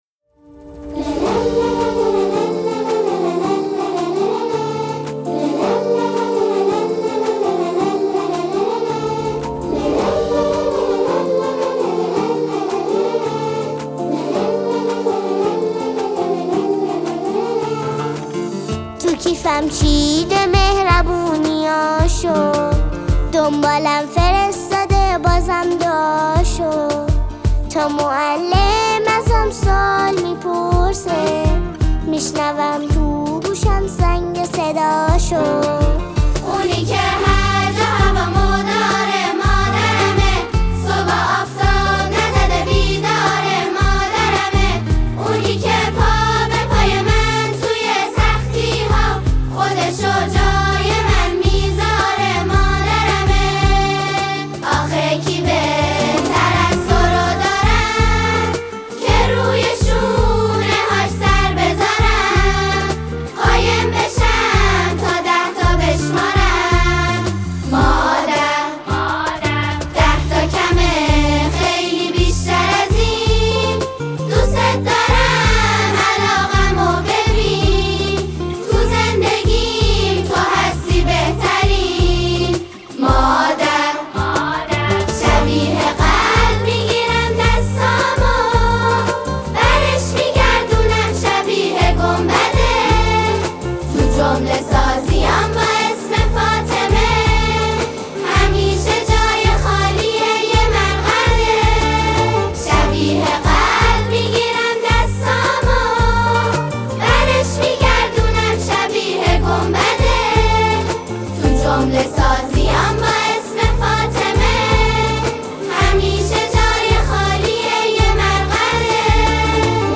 10 دسامبر 2025 2 نظر بیکلام ، سرود